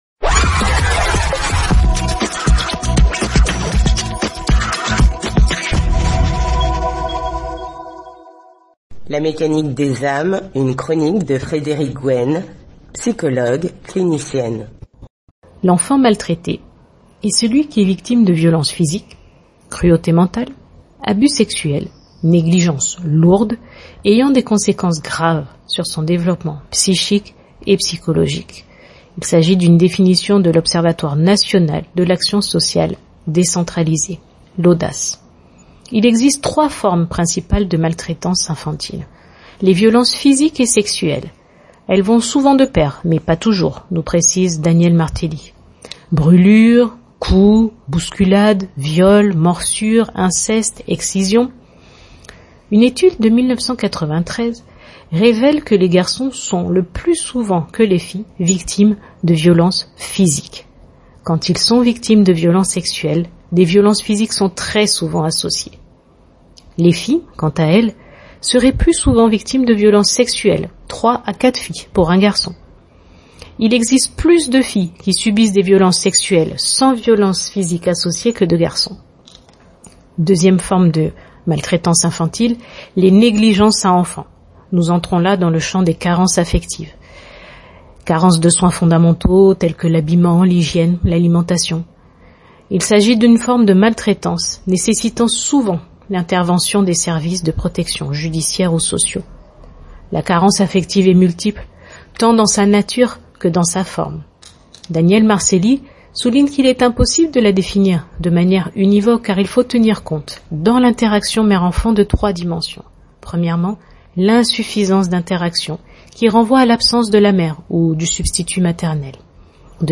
La mécanique des âmes : Les formes de maltraitance infantile] Chronique du mardi 15 Mars